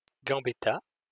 -Gambetta.wav Audio pronunciation file from the Lingua Libre project.